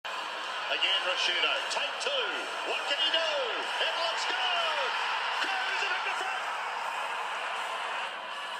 "What can he do?" TV commentary of a Mark Ricciuto goal in Showdown VII, 2000